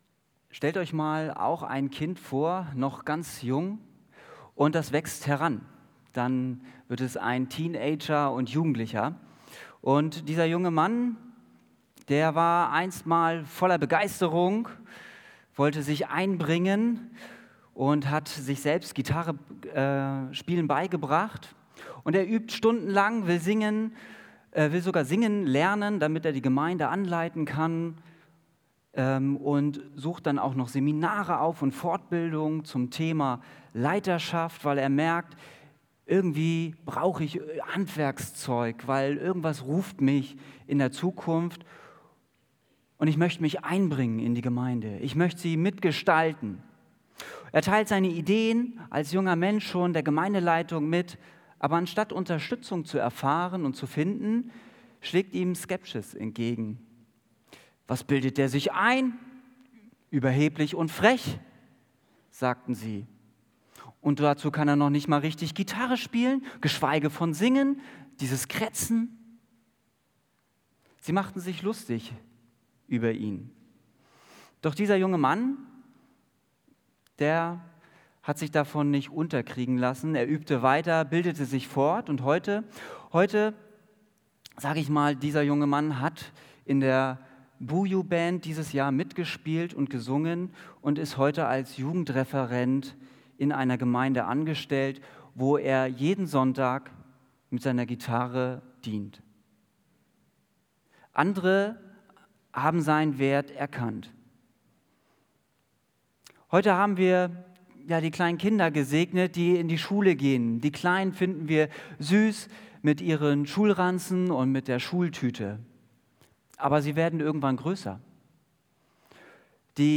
Einschulungs-Gottesdienst mit Abendmahl
Predigt